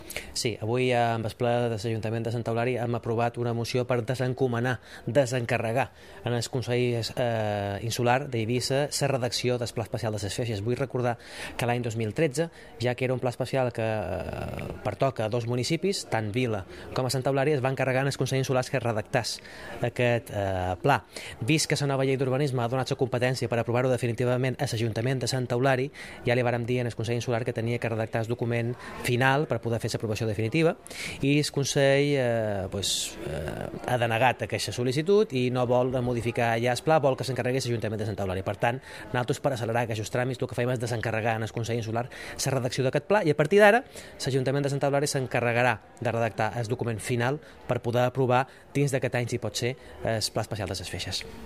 Corte de voz Concejal de Urbanismo Mariano Juan-Plan Especial Ses Feixes